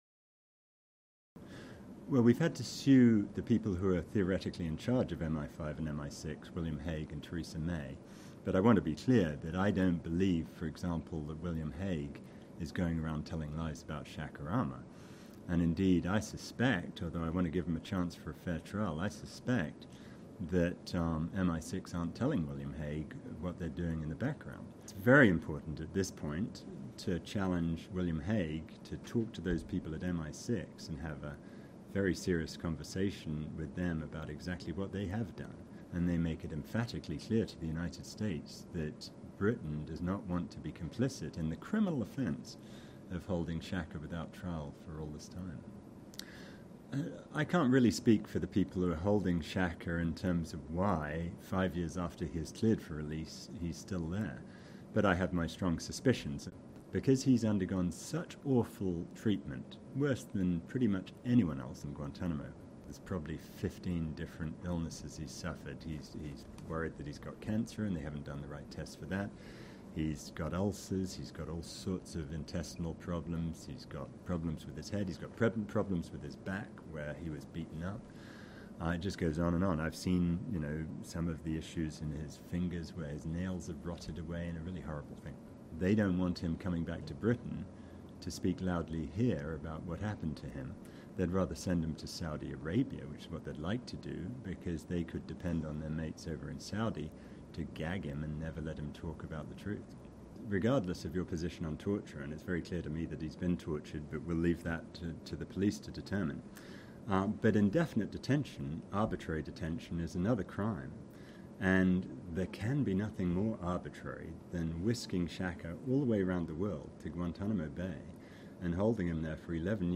This video is an interview with the lawyer Clive Stafford Smith about Shaker Aamer, who has been detained in Guantanamo fo...